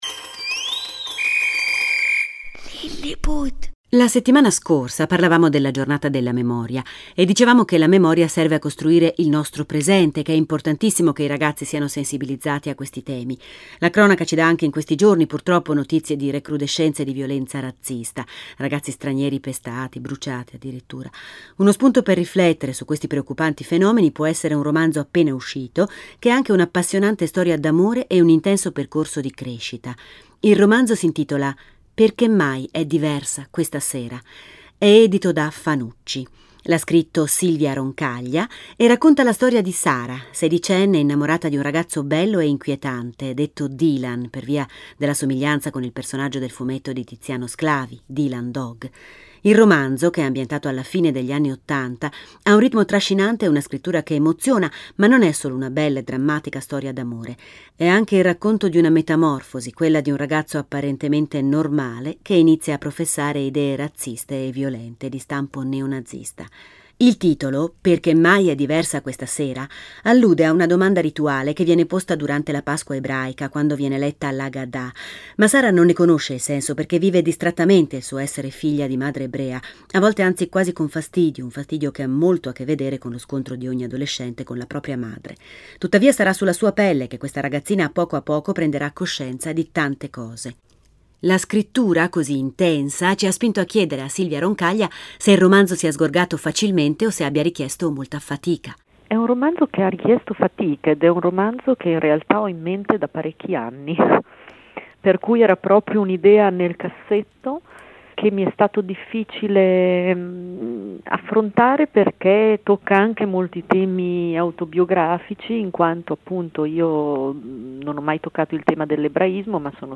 Intervista sulla serie “Perché mai è diversa questa sera?” da Lilliput